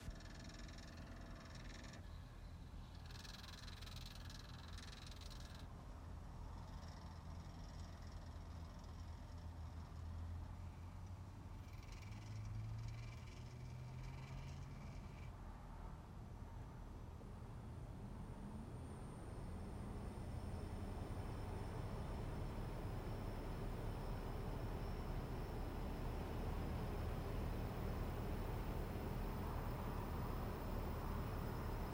Avant chaque démarrage de groupe extérieur, celui-ci émet une série de bruits mécaniques très désagréables.
L’installateur me dit que cela correspond à l’ouverture / fermeture de chaque sortie de l’unité extérieure (il y a effectivement une série de 4 bruits à chaque fois).
Je vous joins un enregistrement audio pris juste avant le déclenchement du compresseur et du ventilateur.
Bruit unité extérieure Daikin.mp3
Oui ces bruits sont normaux, il s'agit bien des détendeurs électroniques.
enregistrement-bruit-ue-daikin.mp3